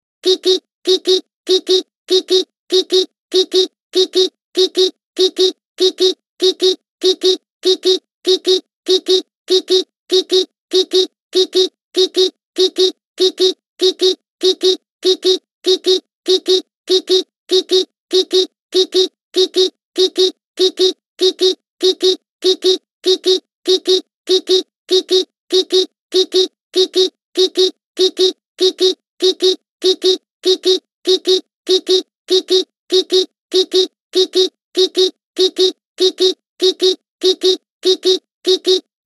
女性の声で「ぴぴっぴぴっ・・・」というビープ音の表現を試みてみます。